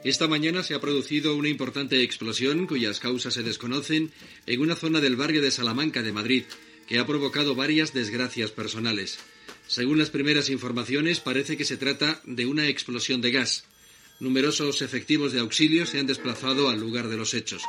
Radio Nacional de España - Butlletí de notícies
Informatiu